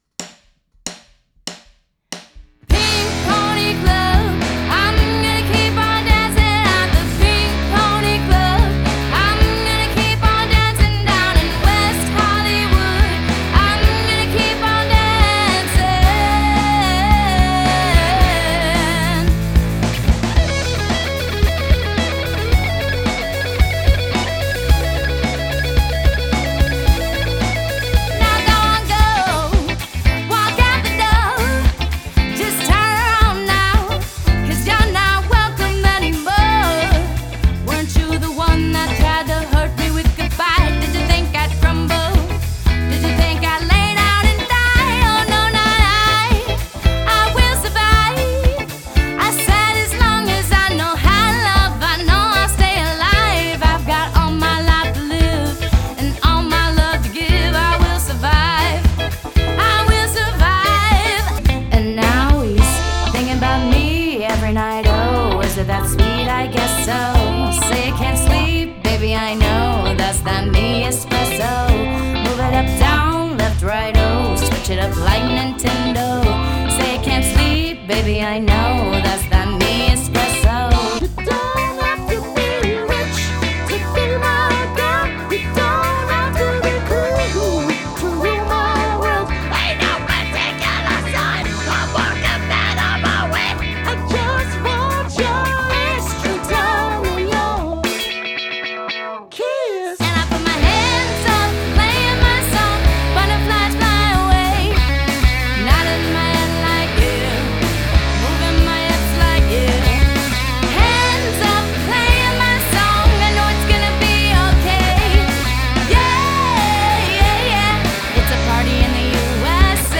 A. 4: guitar, drums, bass, female vocals.